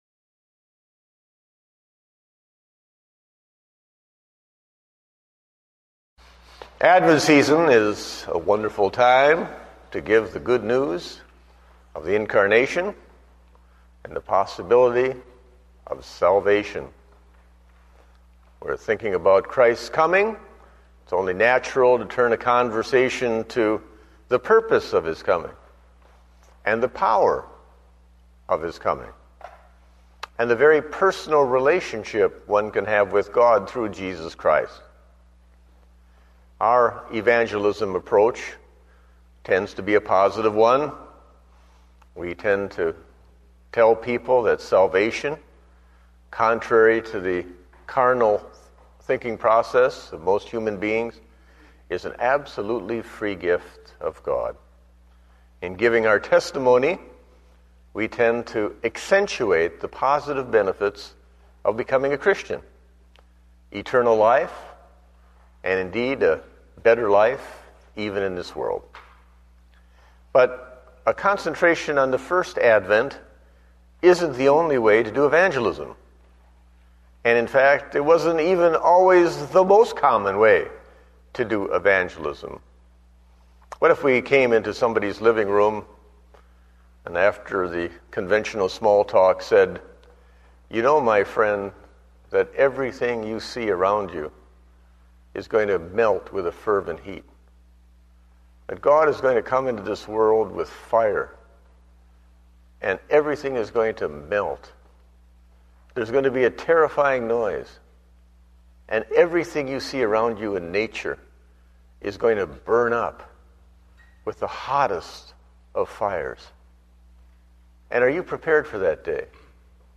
Date: December 6, 2009 (Evening Service)